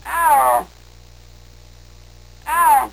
Snow Leopard sound